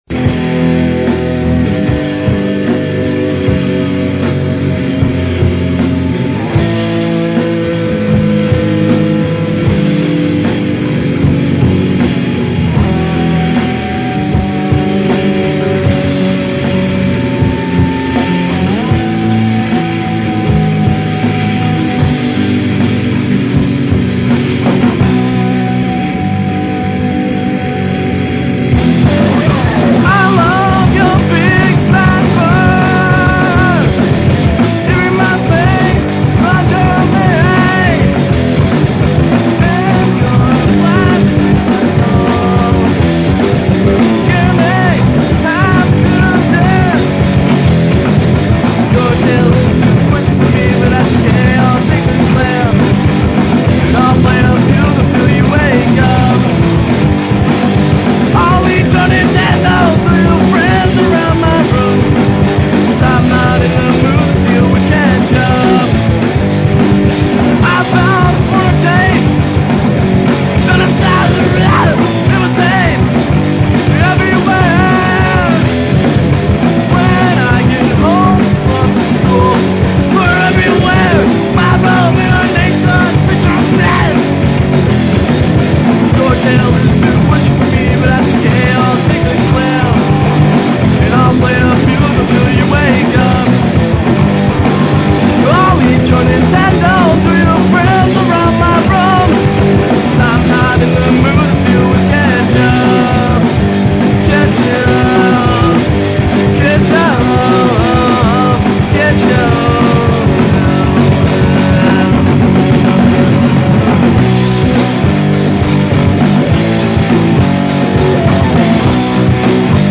pop punk See all items with this value